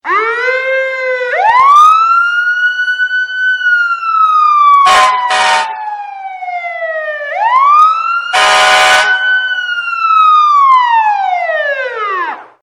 Fire-Siren-and-Horn
Fire-Siren-and-Horn.mp3